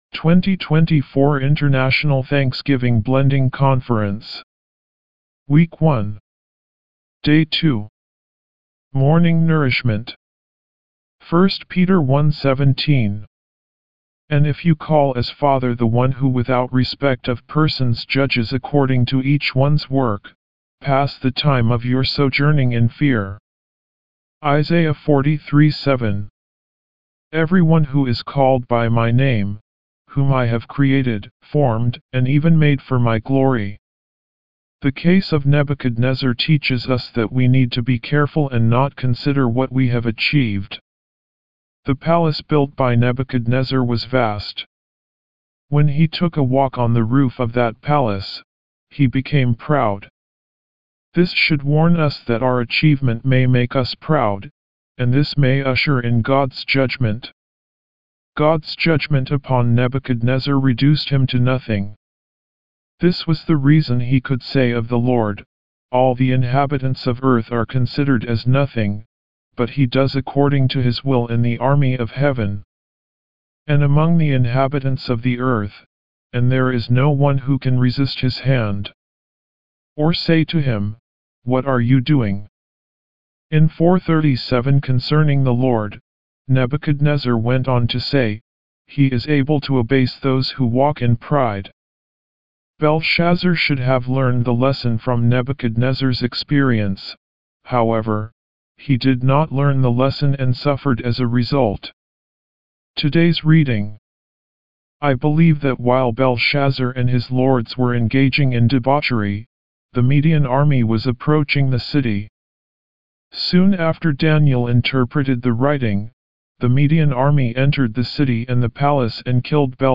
Morning Revival Recitation